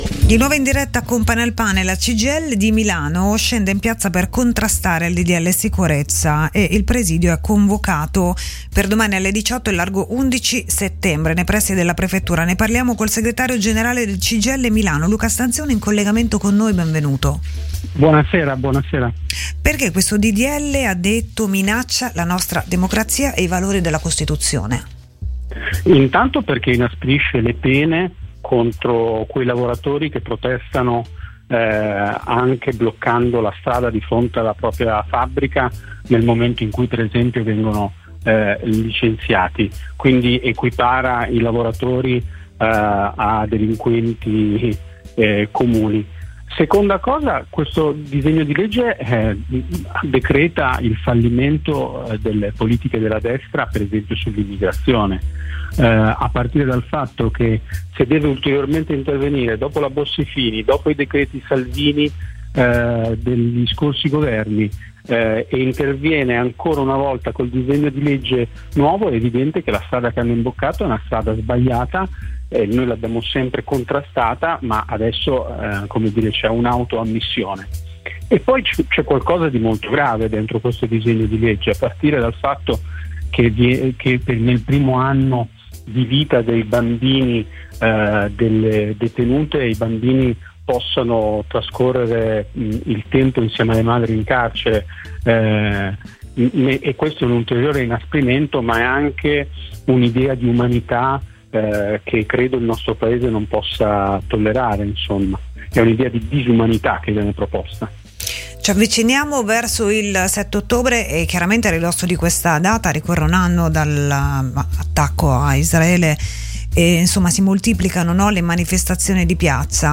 ai microfoni di RadioLombardia.